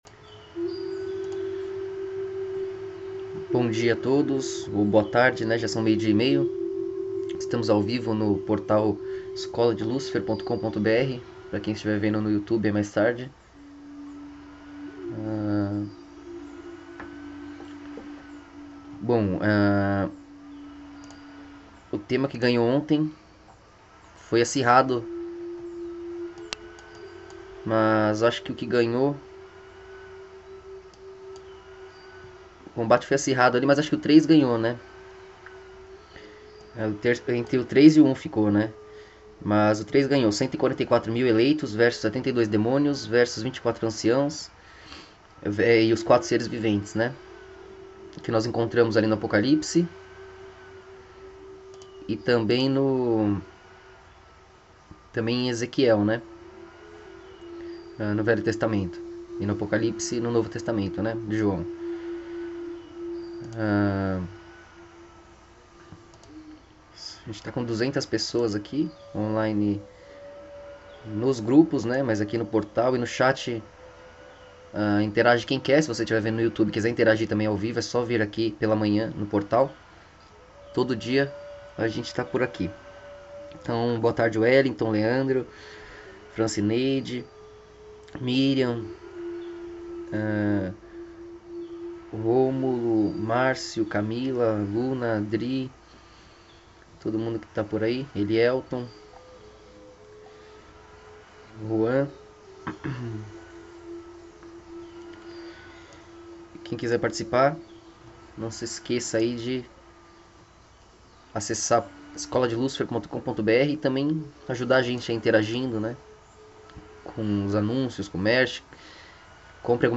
Segue o papo ao vivo de hoje. Dividi em duas partes porque deu pau e me irritou, não vou editar não.